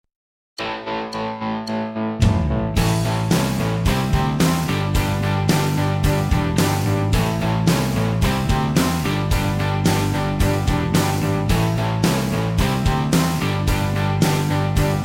Alternative, Instrumental